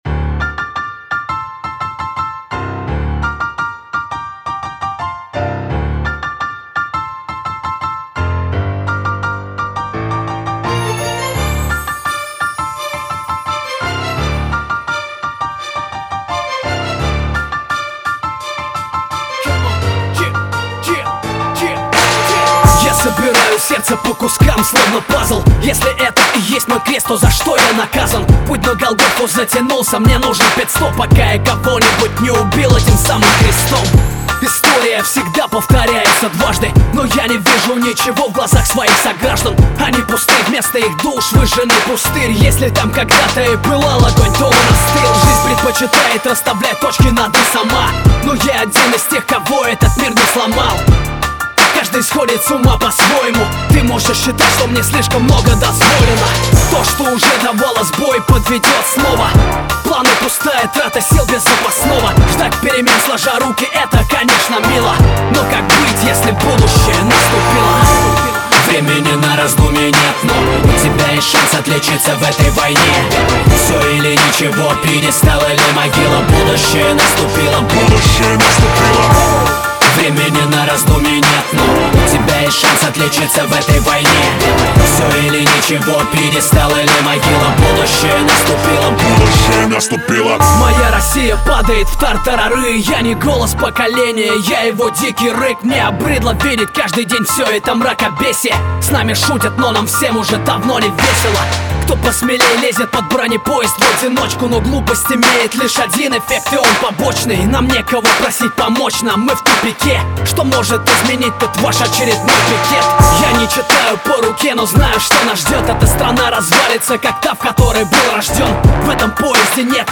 Жанр: Русский рэп, размер 7.70 Mb.